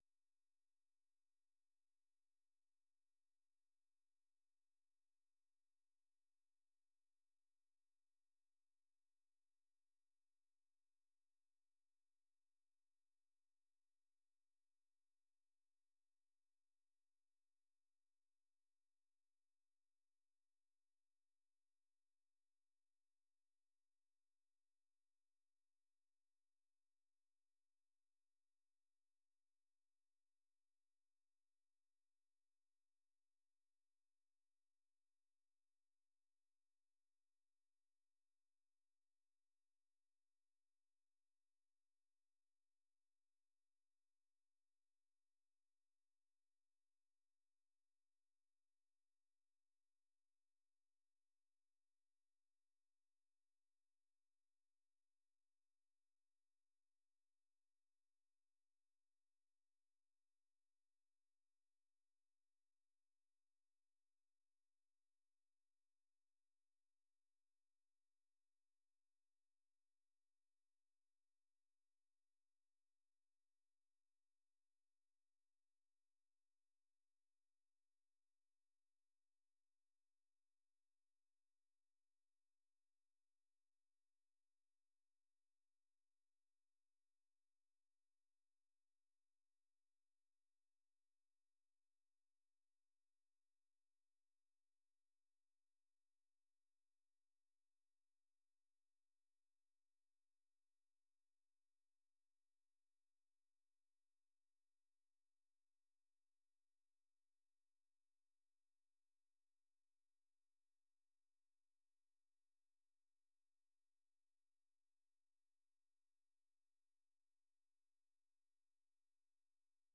ວີໂອເອພາກພາສາລາວ ກະຈາຍສຽງທຸກໆວັນ ເປັນເວລາ 30 ນາທີ.